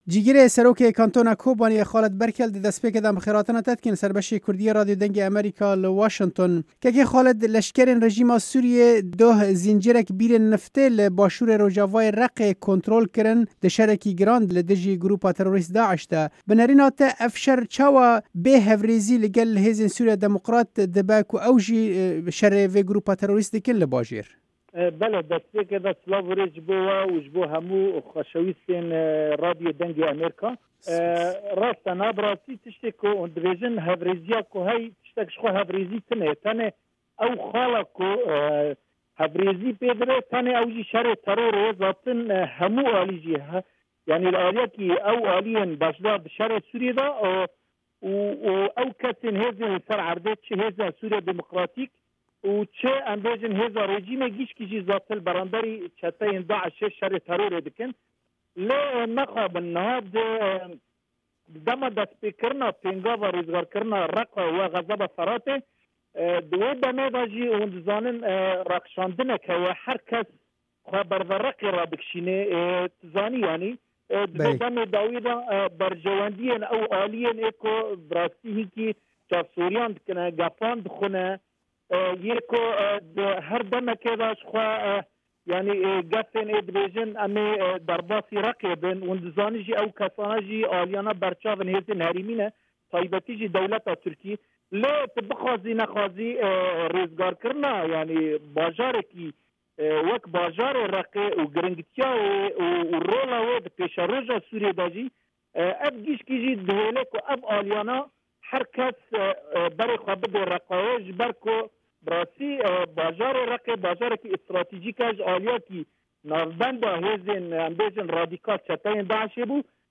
Cîgirê serokê kantona Kobaniyê Xalid Berkel di hevpeyvînekê de li gel Dengê Amerîka ev şerên li ser bêhtirî eniyekî wek şerên berjewendiyan dan nasîn ku her aliyek dixwaze berê kontrola xwe ferehtir bike li wan navçeyan.